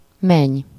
Ääntäminen
Ääntäminen Tuntematon aksentti: IPA: /ˈmɛɲ/ Haettu sana löytyi näillä lähdekielillä: unkari Käännös Ääninäyte Substantiivit 1. belle-fille {f} Muut/tuntemattomat 2. bru {f} France Luokat Perhe Substantiivit